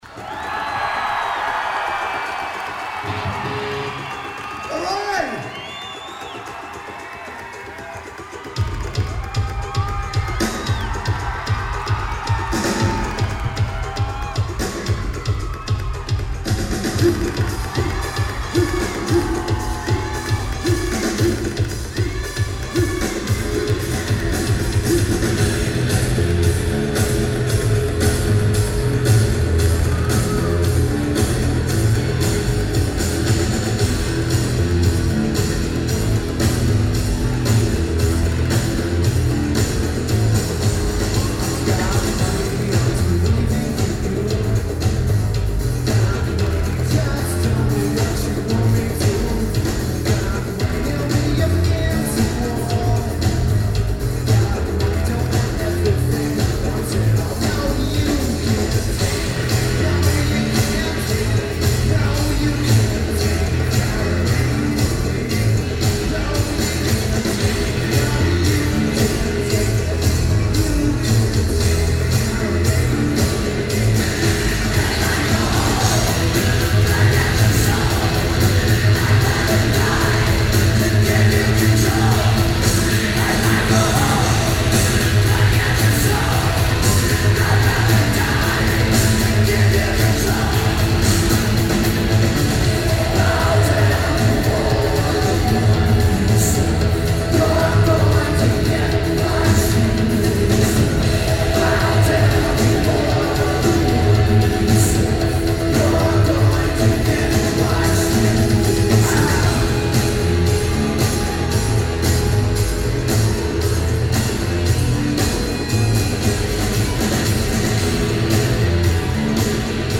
Moore Theatre
Lineage: Audio - AUD (Sonic Studios + Sony TCD-D3)
Sound is amazing.